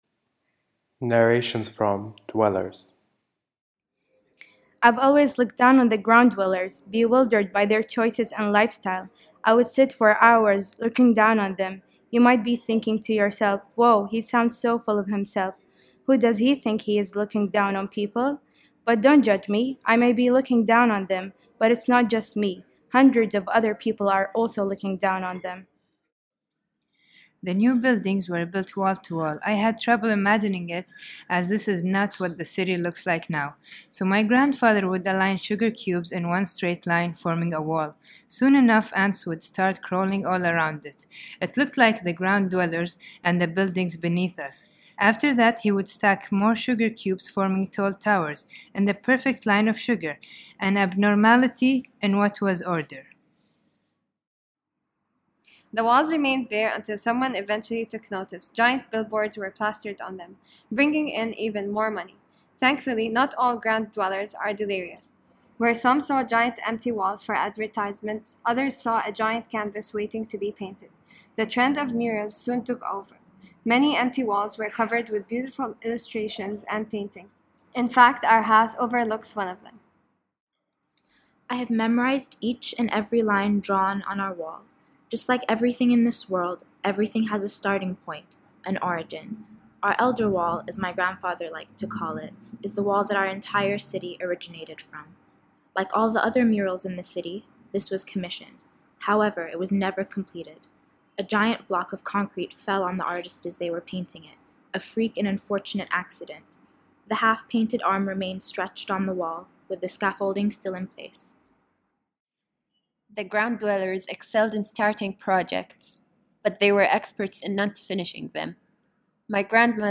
Audio Narrations
Spaces in Narratives Exhibit at AlMakan Cube gallery.
Recording sessions were conducted on a six day residency during which visitors were welcomed to explore the concepts behind each story and participate in building the narrative.
After learning the scene a short audio outtake was recorded.